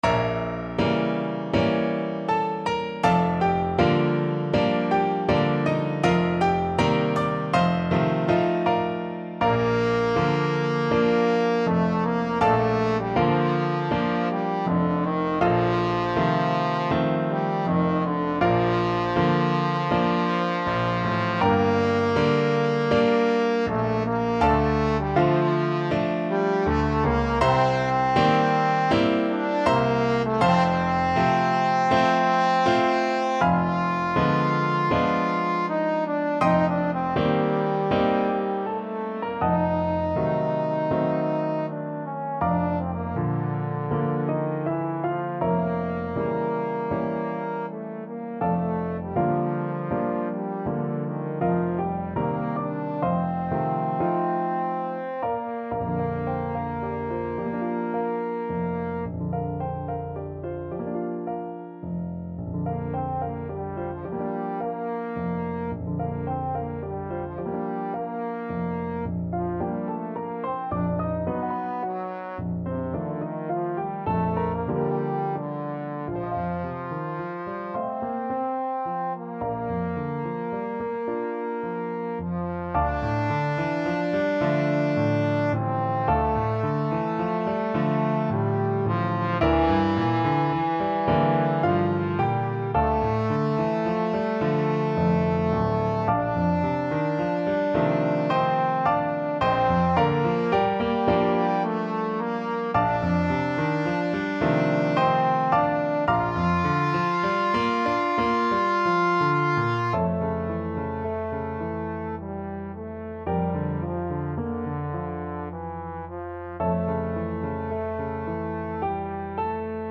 Trombone Classical